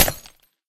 glass3.ogg